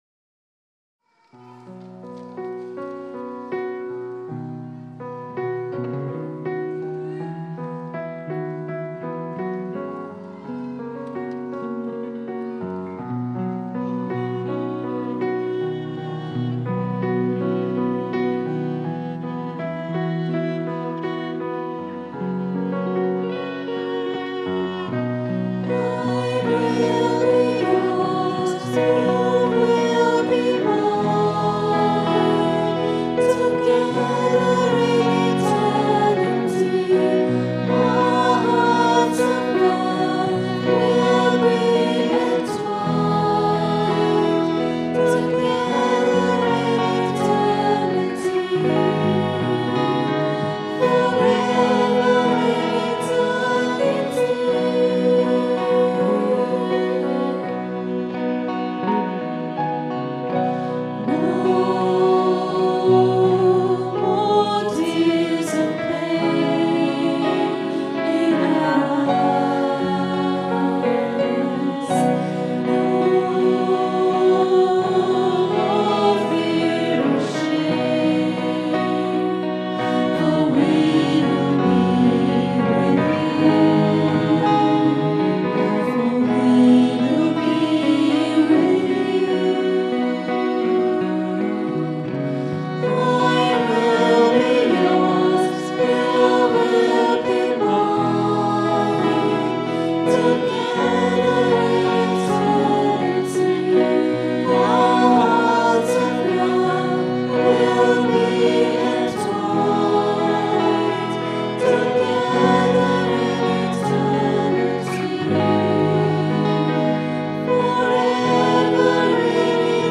Recorded at 10 am Mass, 1st July 2012 on a Zoom H4 digital stereo recorder.